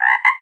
animalia_frog_3.ogg